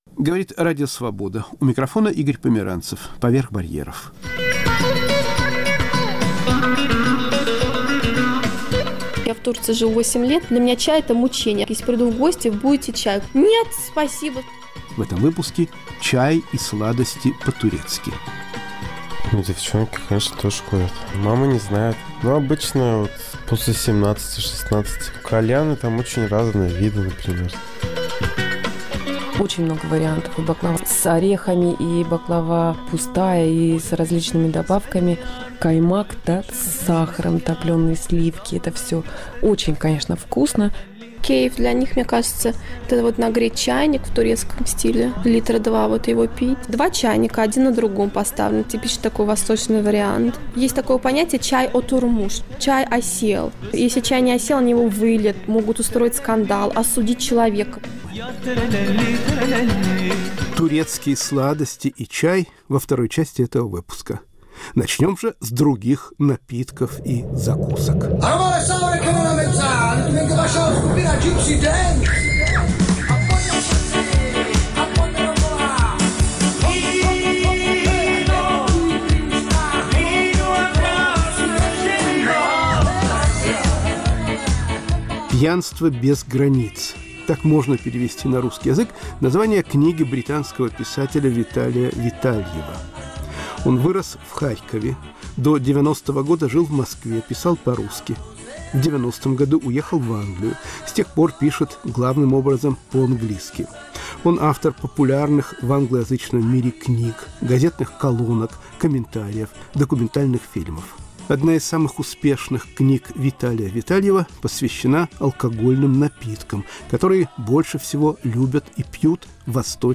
Сладости и чай по-турецки. Рассказывают жители Стамбула.